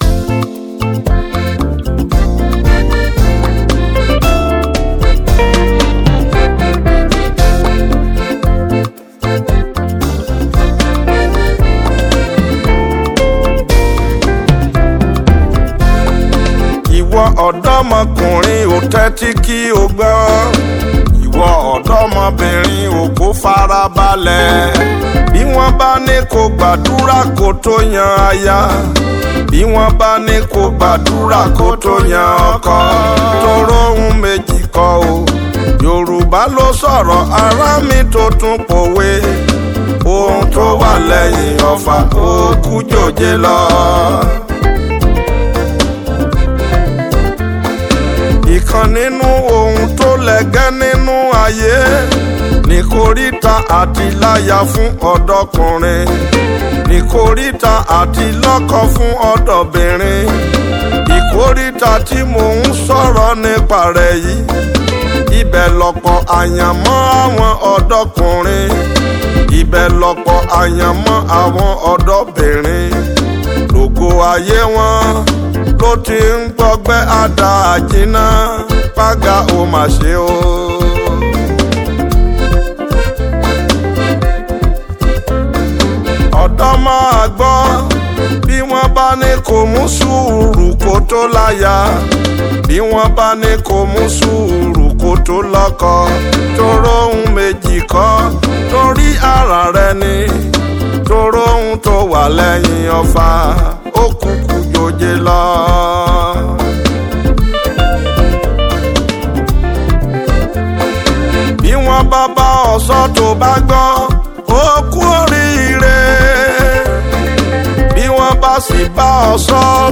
a powerful worship song to uplift and inspire you.